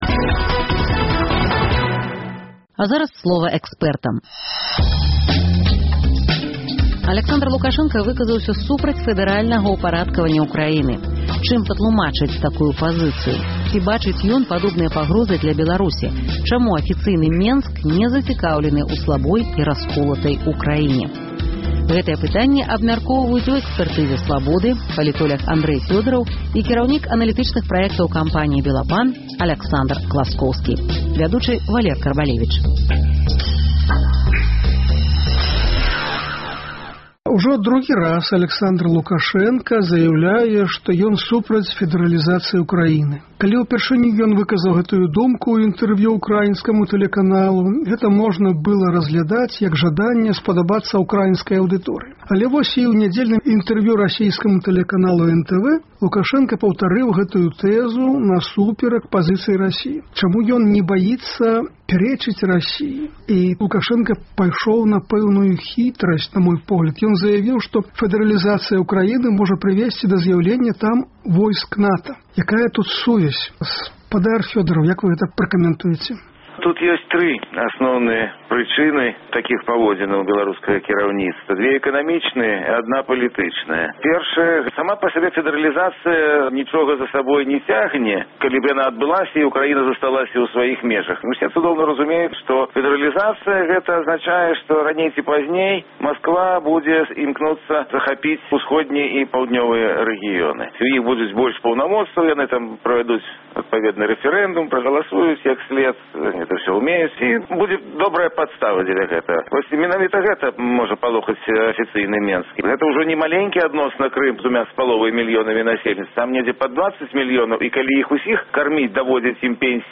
Ці бачыць ён падобныя пагрозы для Беларусі? Чаму афіцыйны Менск не зацікаўлены ў слабой і расколатай Украіне? Гэтыя пытаньні абмяркоўваюць за круглым сталом